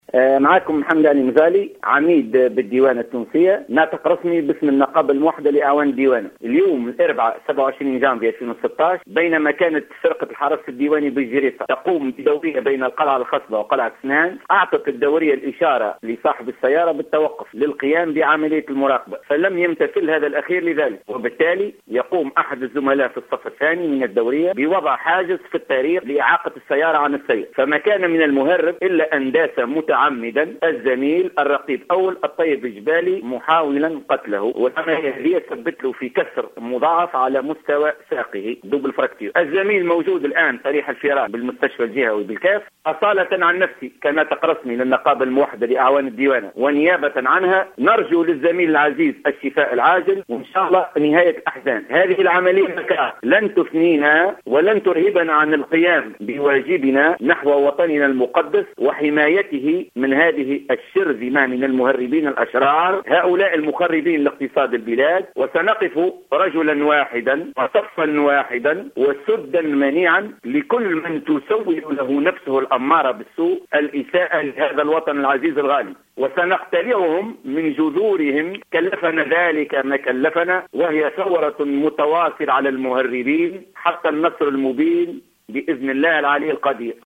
في تصريح هاتفي للجوهرة أف أم